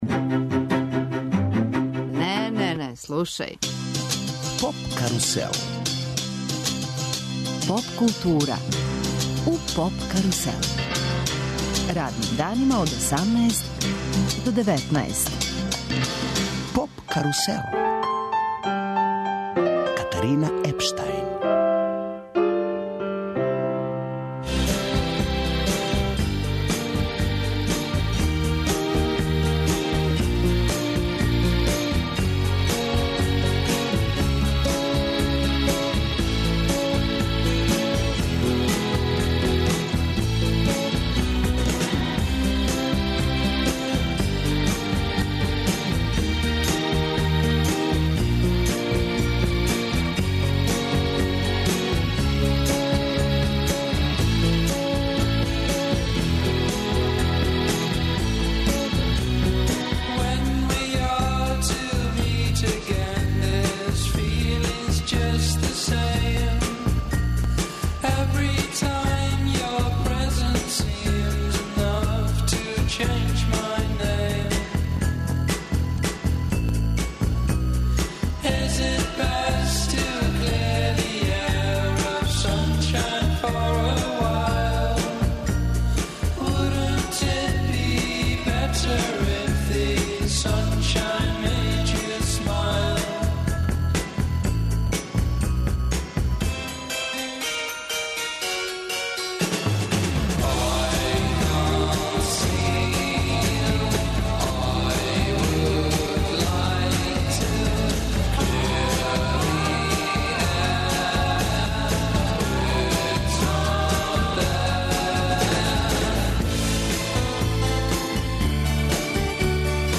Радио Београд 1 и ове године уживо реализује емисије са фестивала Eurosonic, из Холандије. Eurosonic Noorderslag је музички фестивал посвећен изградњи европске поп сцене.